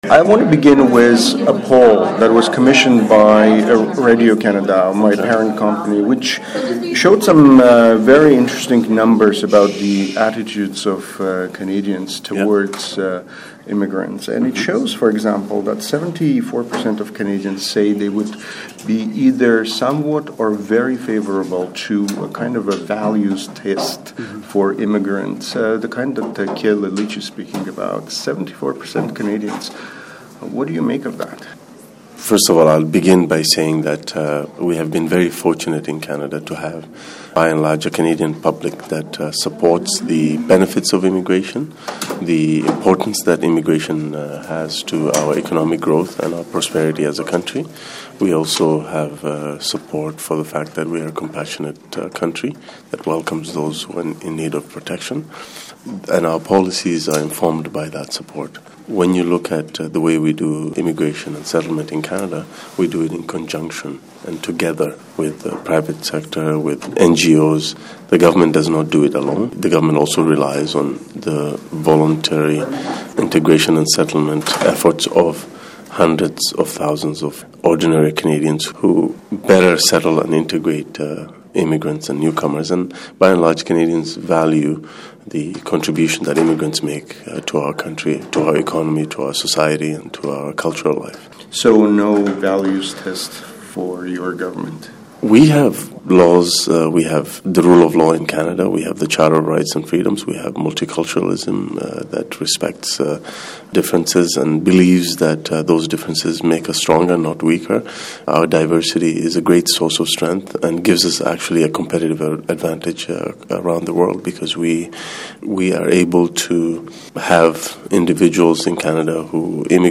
Speaking to Radio Canada International in an exclusive interview on the sidelines of the 19th National Metropolis Conference on Friday, Hussen reacted to a recent poll commissioned by Radio-Canada.
Listen to Ahmed Hussen's interview